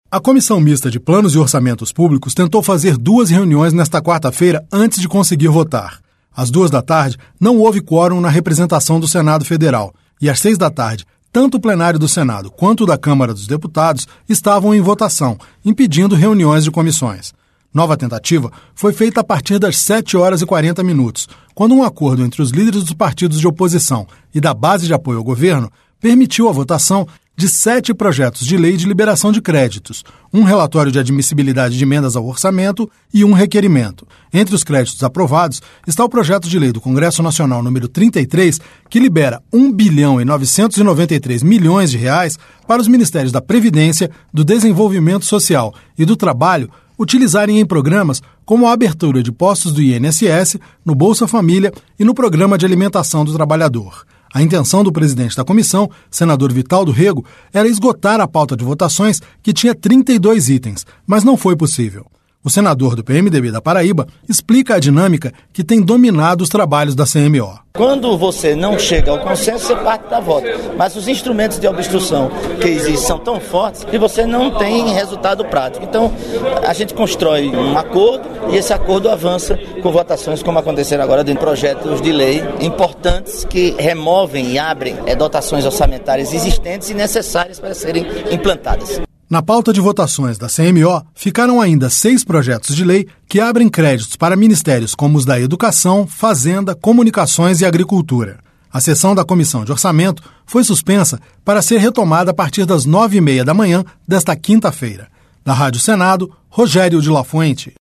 O senador do PMDB da Paraíba explica a dinâmica que tem dominado os trabalhos da C-M-O. (SENADOR VITAL DO RÊGO): Quando você não chega ao consenso, você parte pra voto.